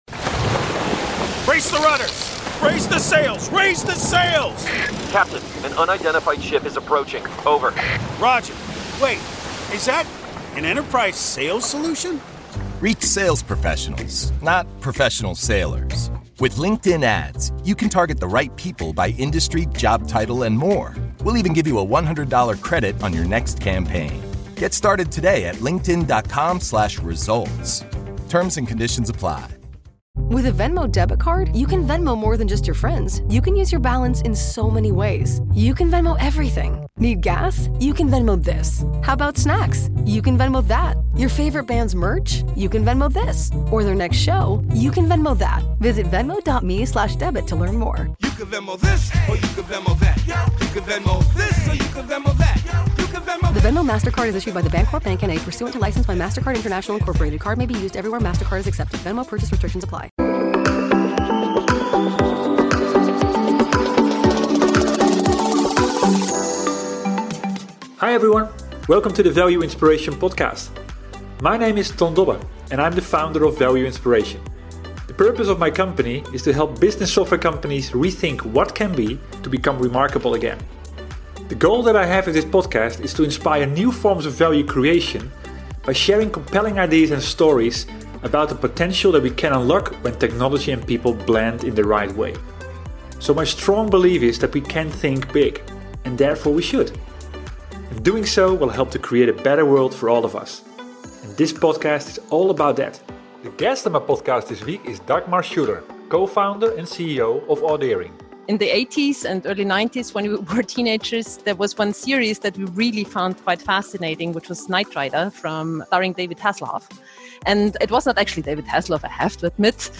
This podcast interview focuses on product innovation with Audio Intelligence that has the power to impact human wellbeing on a global scale.